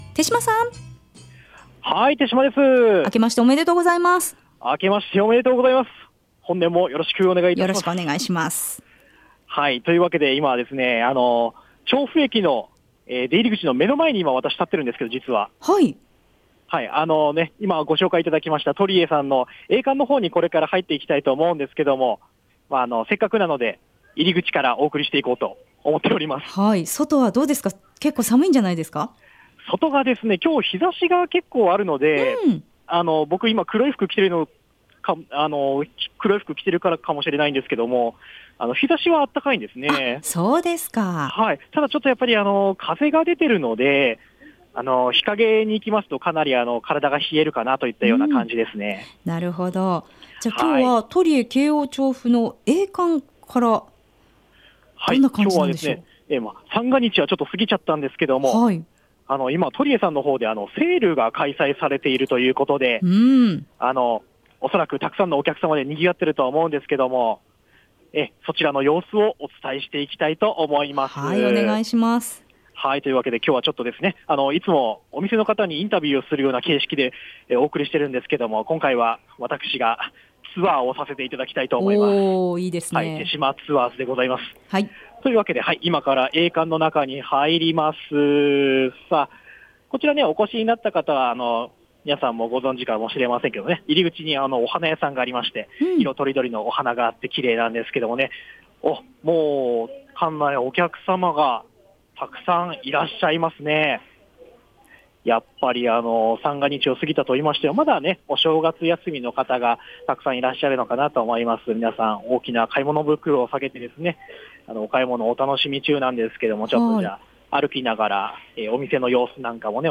新年最初の木曜日街角レポートは「トリエ京王調布A館」より初売りセール中の館内の様子をお届け！